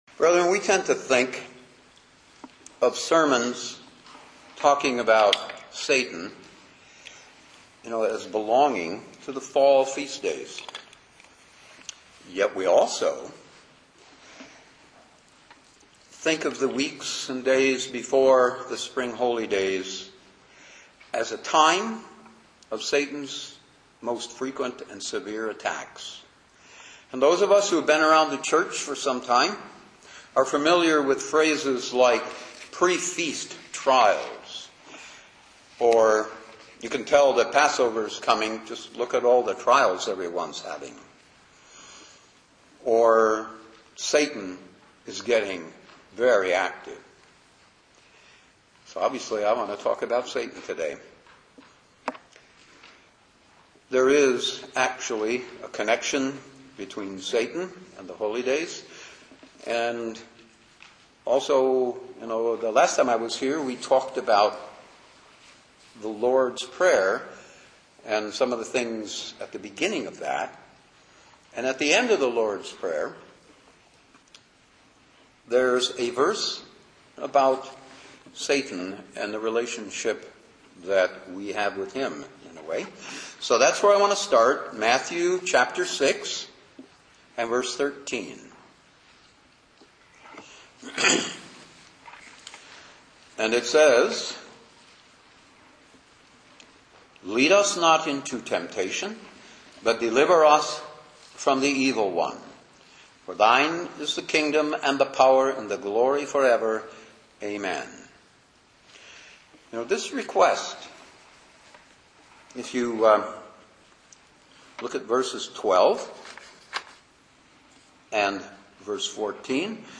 Given in Detroit, MI
UCG Sermon Studying the bible?